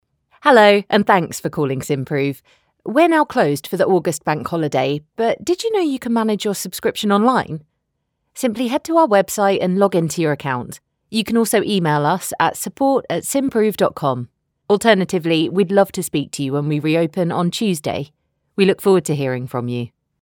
Inglés (Británico)
Travieso, Seguro, Amable, Cálida, Profundo
Telefonía